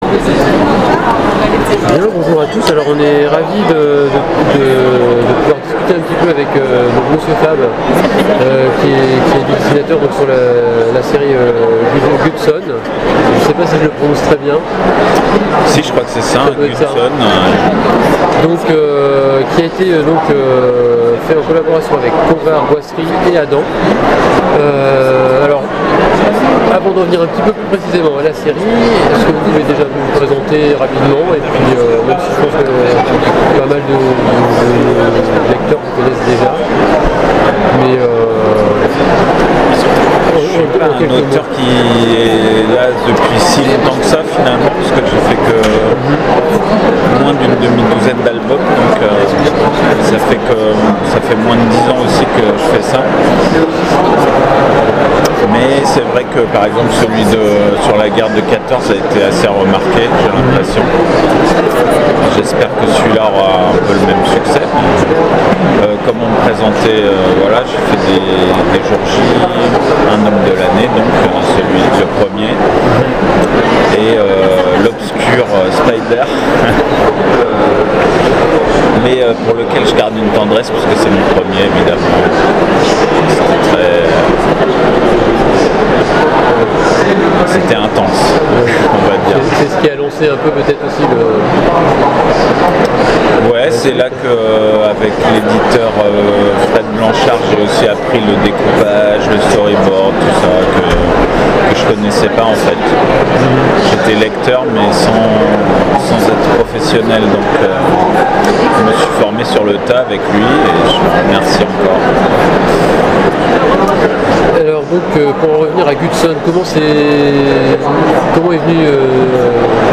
Interview 2017